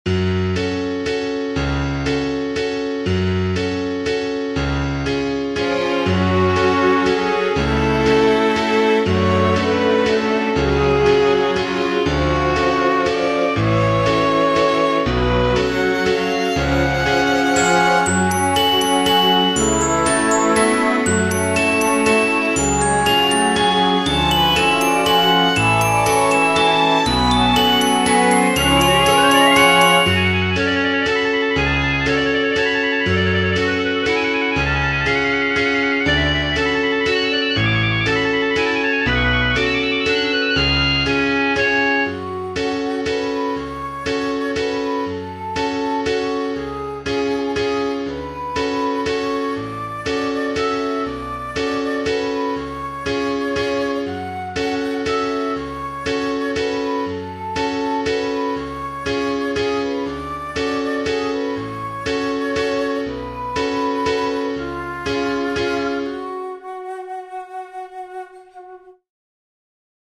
Waltz in F# minor